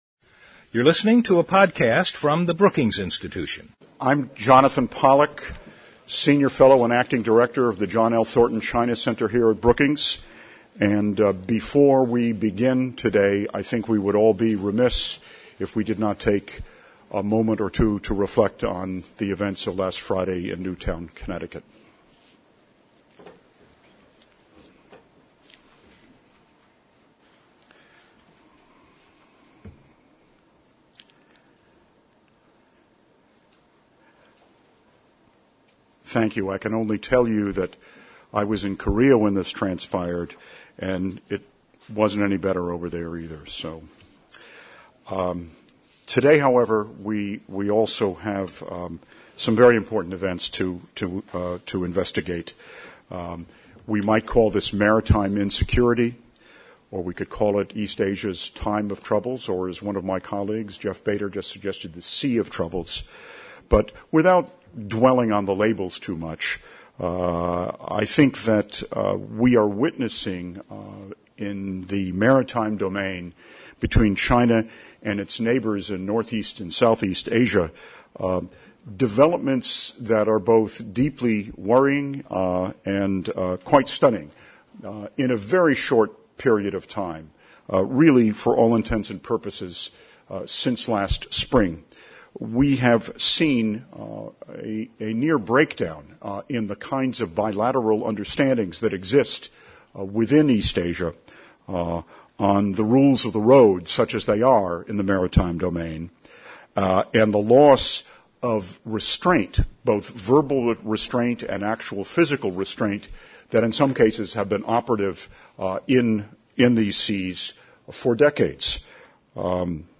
On December 17, the John L. Thornton Center at Brookings hosted a discussion on China’s maritime disputes and the future of U.S.-China relations featuring leading experts on Chinese foreign policy and maritime strategy, and an address by The Honorable Kevin Rudd, the 26th prime minister of Australia and former minister of foreign affairs.